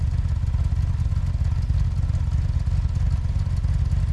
rr3-assets/files/.depot/audio/Vehicles/4cyl_01/4cyl_01_idle.wav
4cyl_01_idle.wav